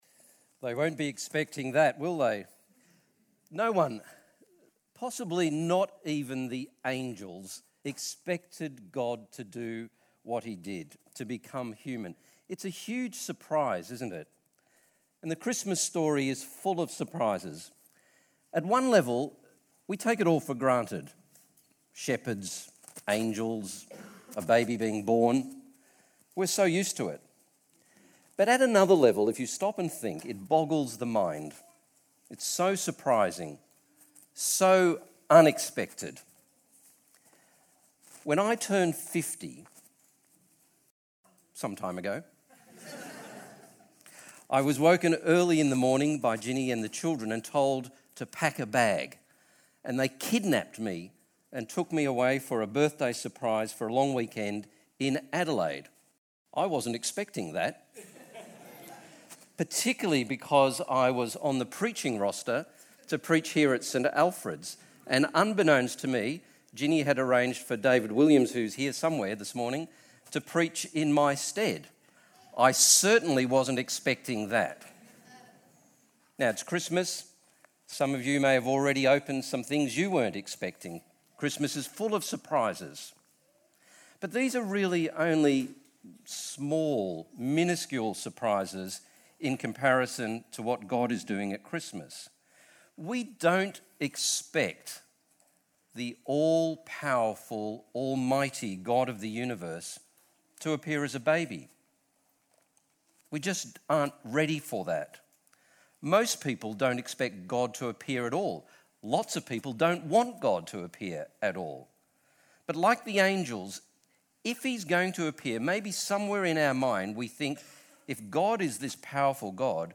Standalone Sermon